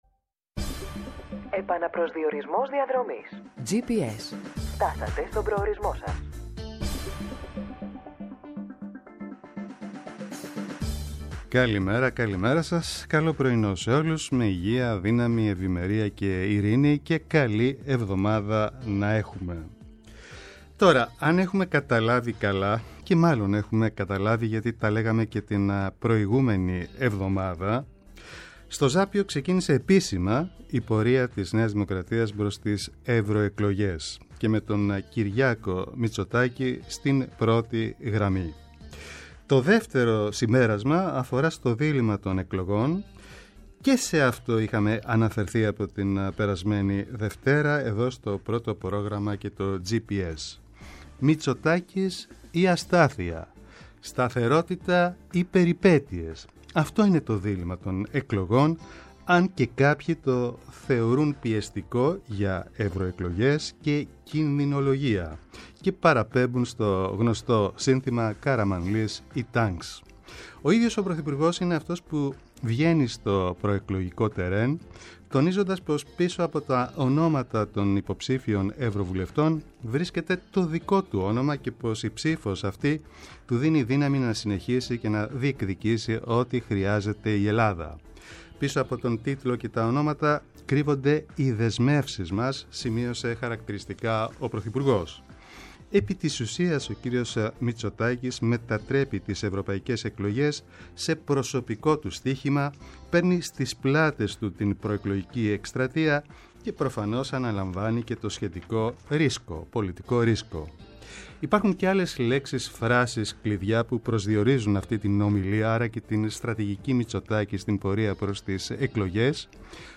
Καλεσμένοι στην εκπομπή: Ο υπουργός Μετανάστευσης και Ασύλου, καθηγητής Διεθνών Σχέσεων στο Πάντειο Πανεπιστήμιο Δημήτρης Καιρίδης, και ο ομότιμος καθηγητής Συνταγματικού Δικαίου του Πανεπιστημίου Αθηνών Νίκος Αλιβιζάτος.